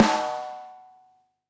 Snare m201 8.wav